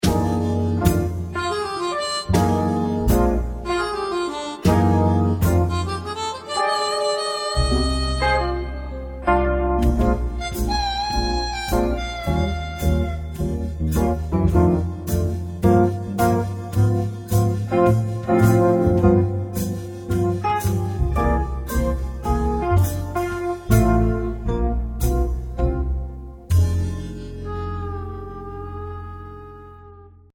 Please note: These samples are not of CD quality.